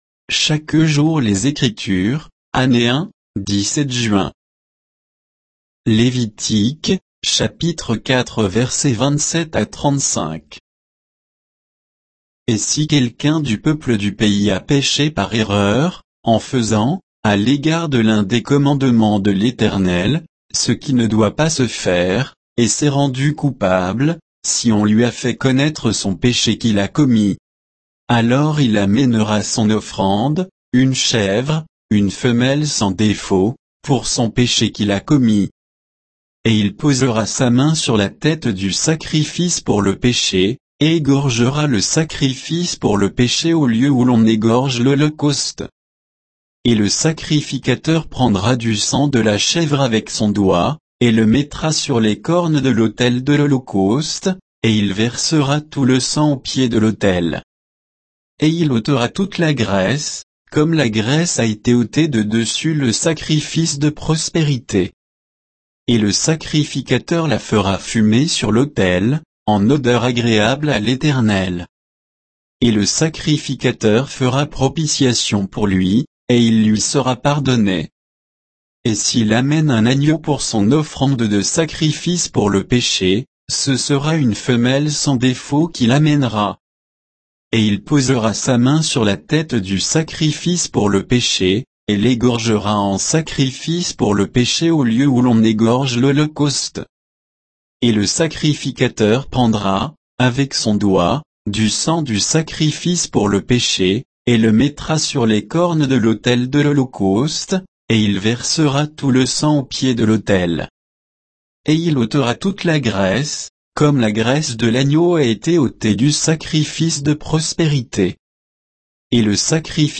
Méditation quoditienne de Chaque jour les Écritures sur Lévitique 4, 27 à 35